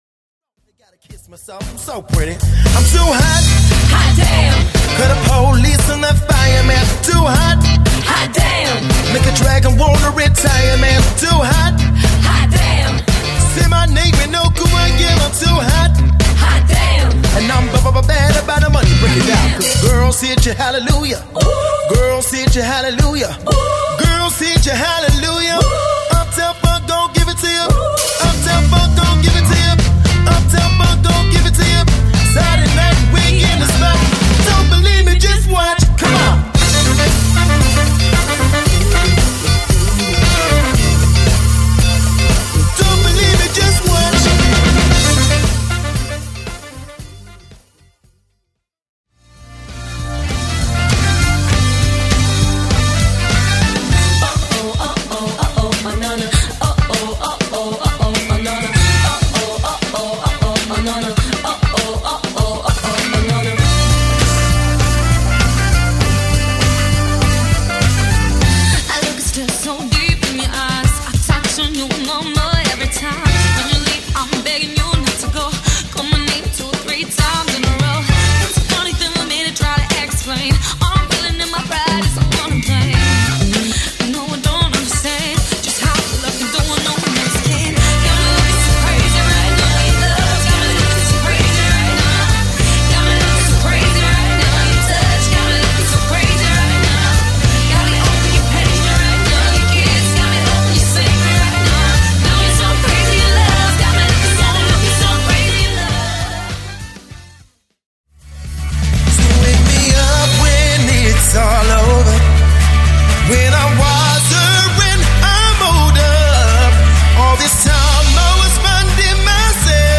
• Unique medleys with tight harmonies and choreography